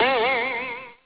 spring.wav